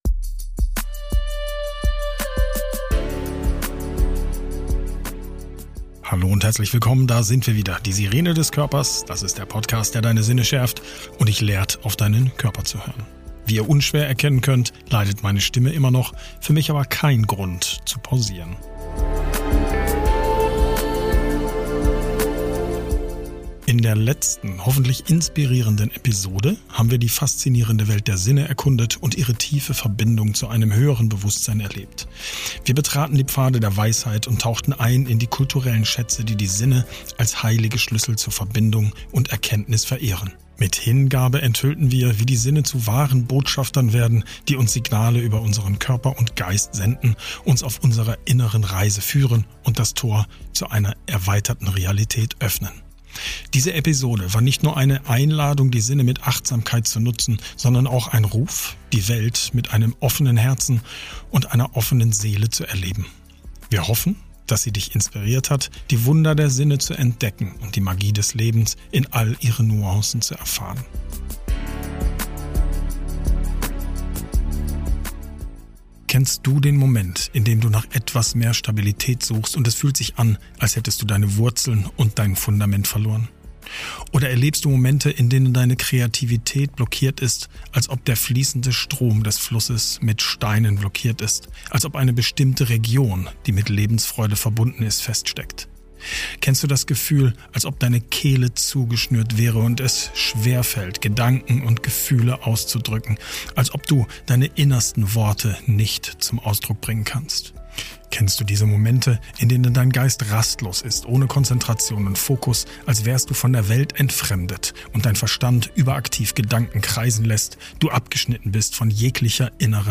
In dieser mysteriösen Reise werden wir von einer Expertin für Chakrenharmonisierung begleitet, um die verborgenen Pforten der inneren Energie zu erkunden.